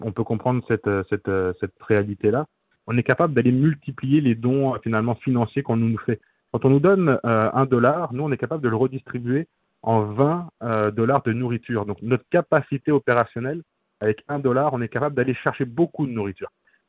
C’est ce qu’il a expliqué sur VIA 90.5 FM.